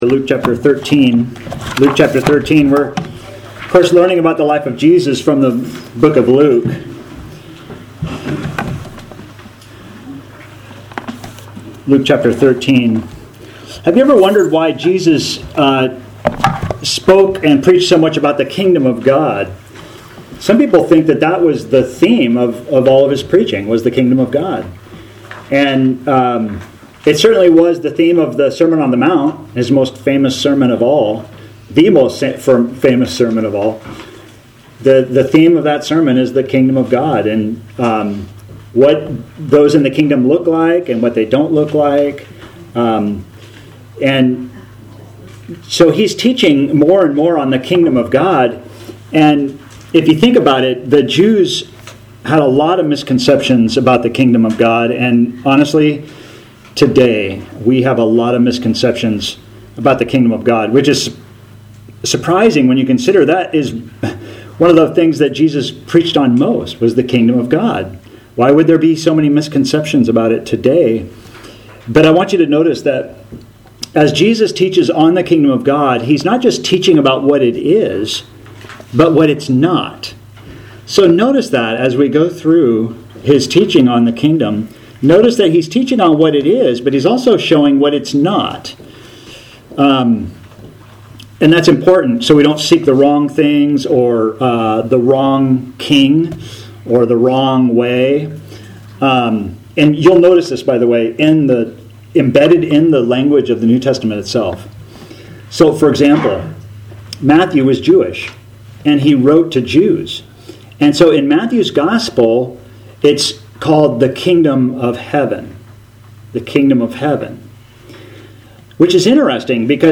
Author jstchurchofchrist Posted on March 22, 2024 Categories Sermons Tags Jesus , Luke - Gospel For All